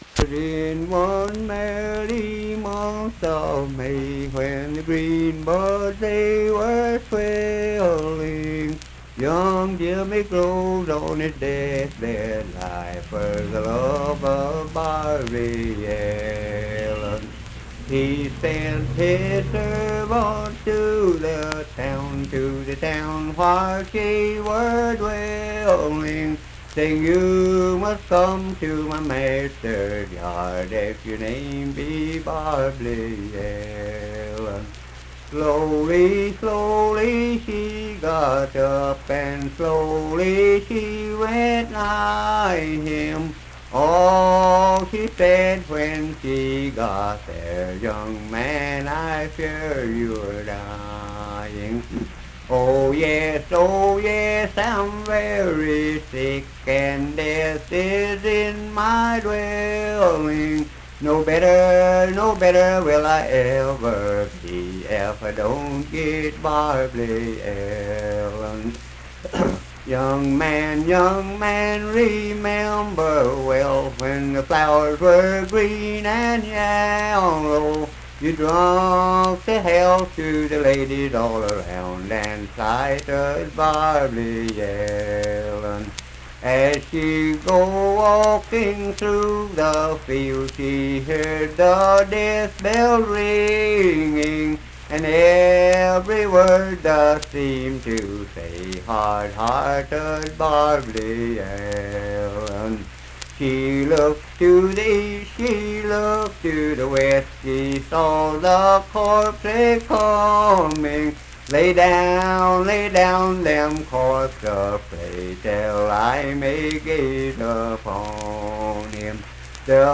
Unaccompanied vocal and banjo music
Verse-refrain 10(4).
Voice (sung)
Clay County (W. Va.), Clay (W. Va.)